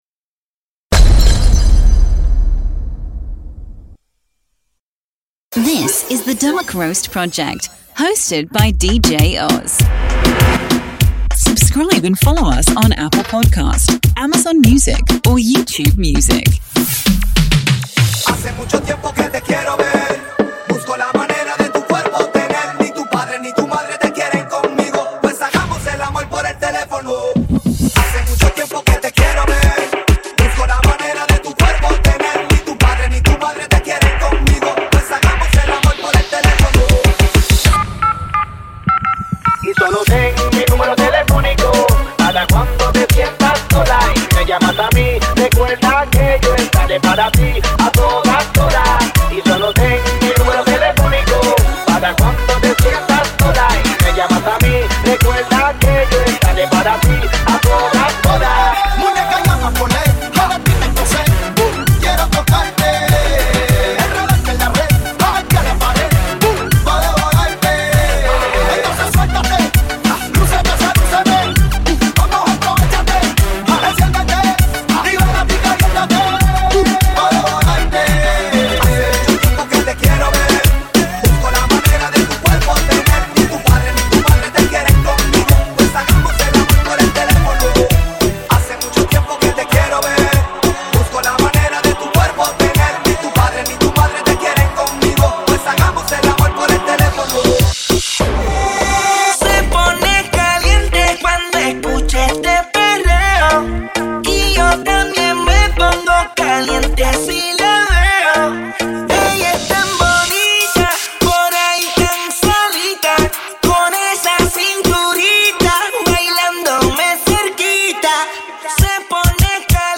LATIN CLUB VIBES